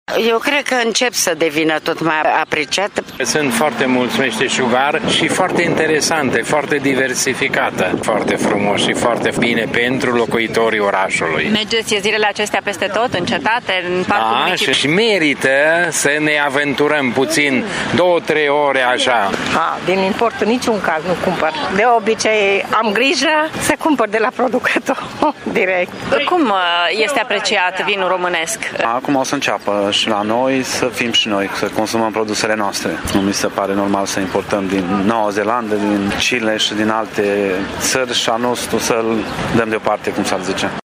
Imediat ce s-au întins corturile în centrul orașului, acestea au fost luate cu asalt de târgumureșeni care, în ultimul timp, sunt tot mai mari consumatori de produse locale și tradiționale: